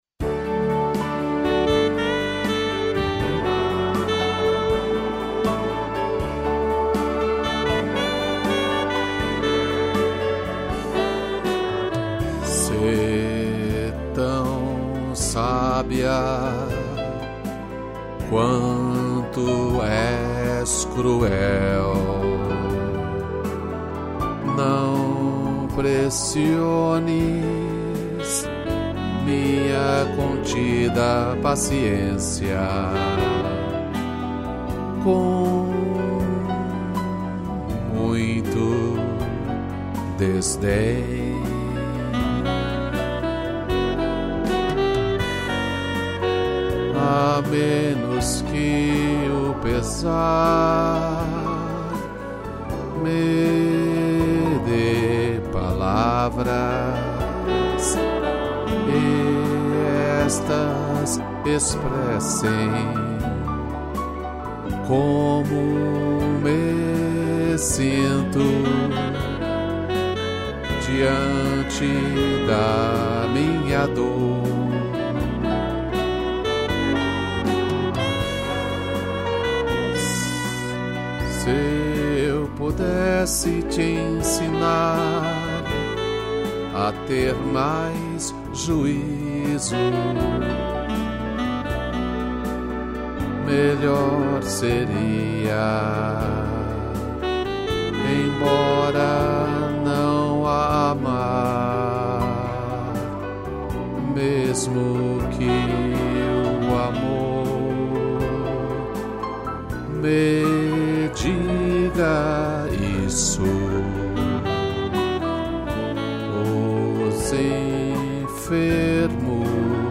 piano, sax e órgão